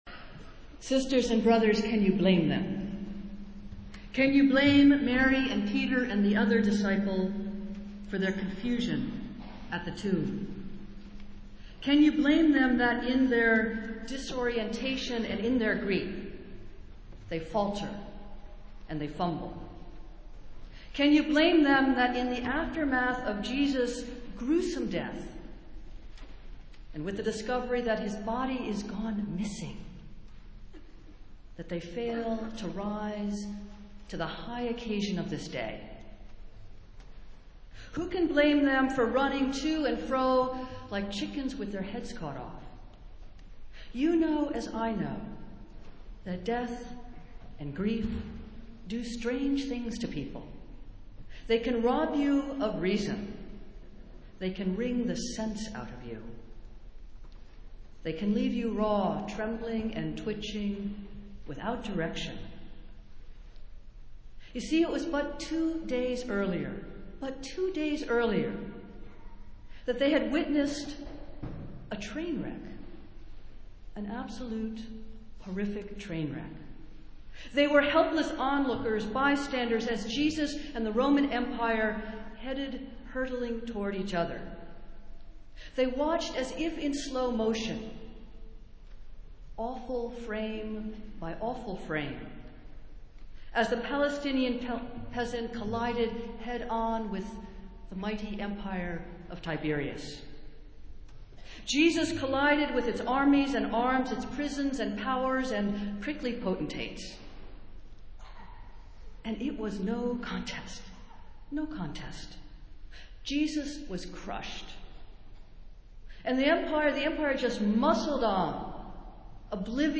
Festival Worship - Easter Sunday
Easter Reflection